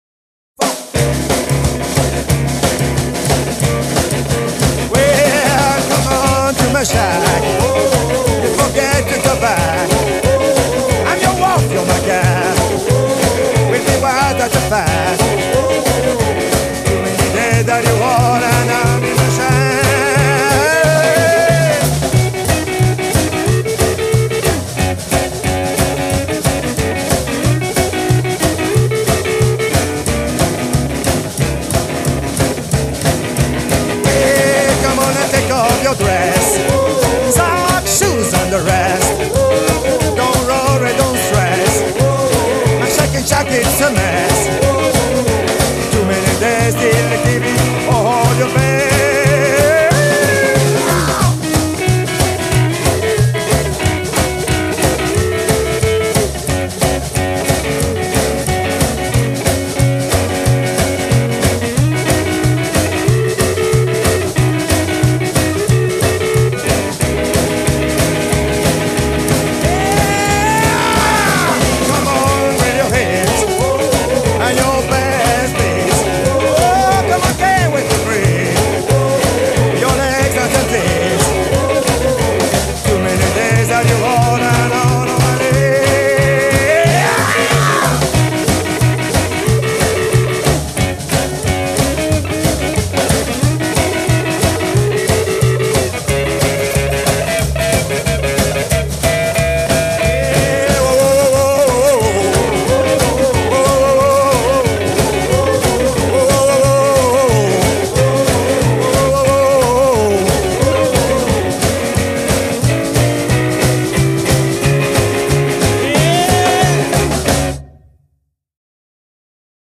дискография рокабилли трио Англия-Франция